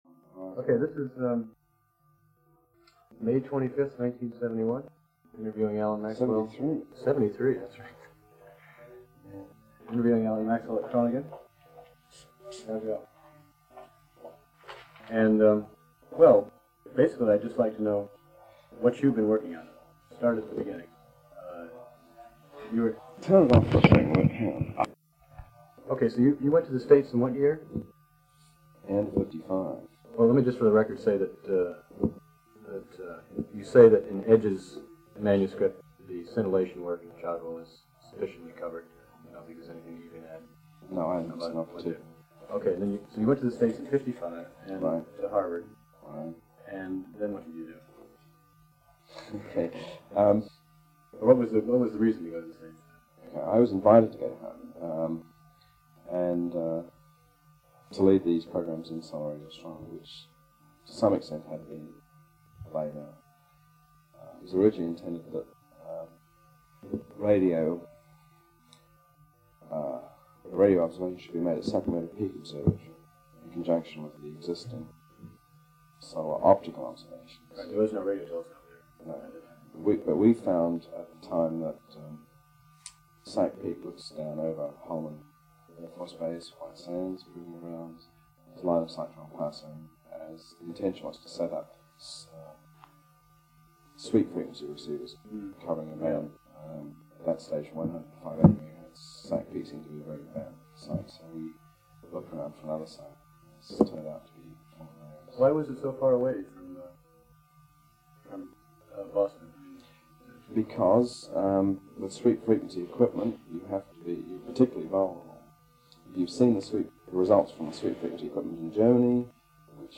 Interview
Oral History
Audio cassette tape